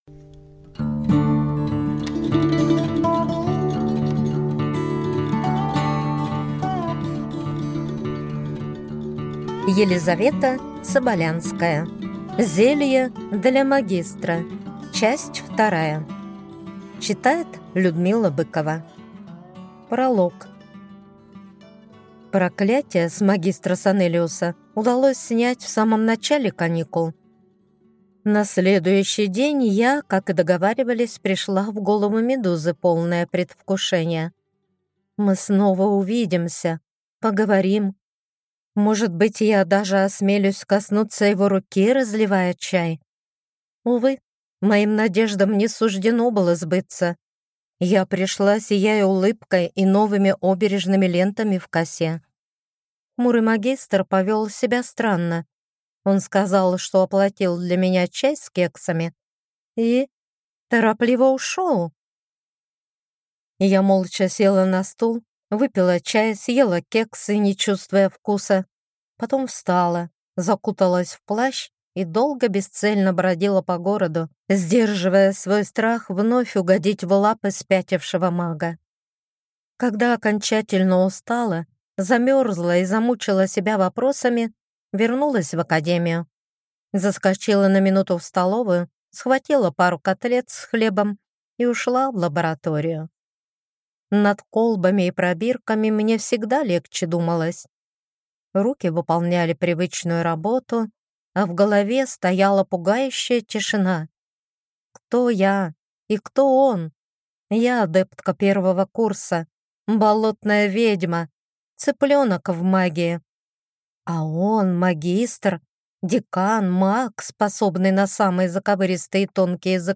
Аудиокнига Зелье для магистра 2 | Библиотека аудиокниг